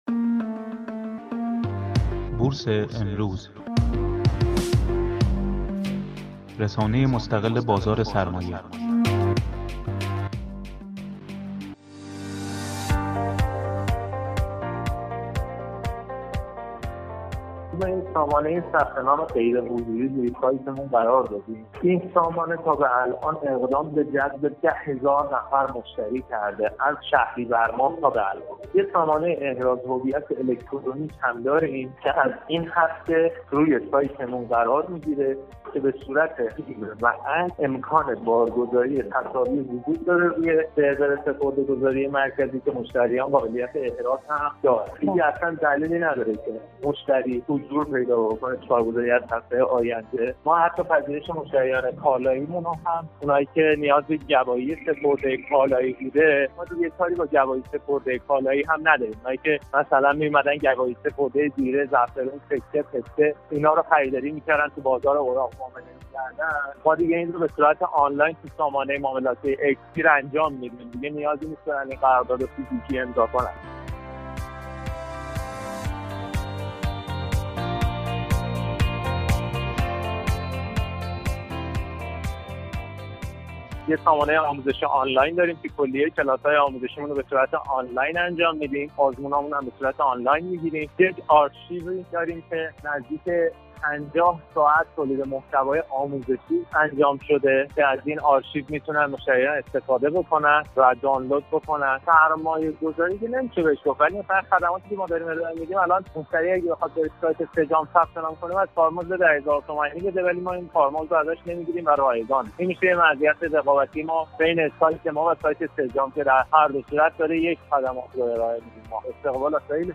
در گفت‌وگو با خبرنگار بورس امروز، درباره خدمات غیرحضوری این کارگزاری توضیح داد